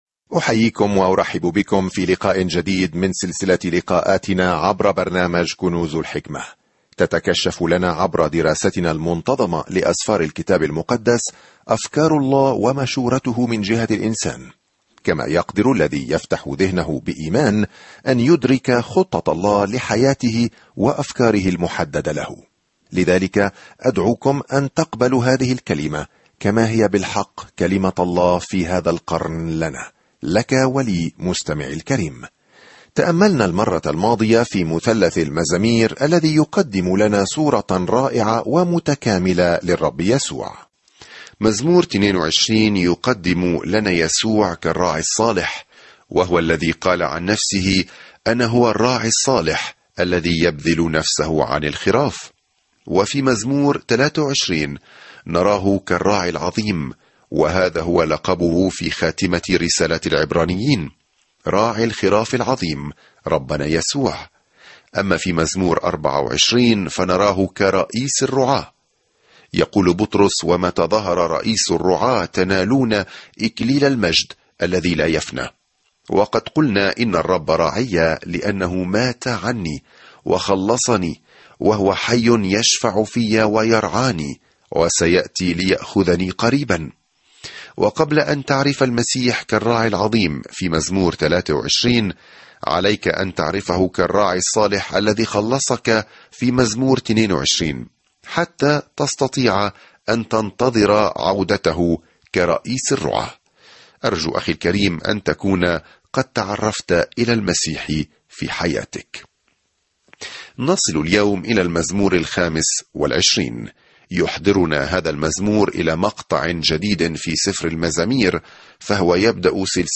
سافر يوميًا عبر المزامير وأنت تستمع إلى الدراسة الصوتية وتقرأ آيات مختارة من كلمة الله.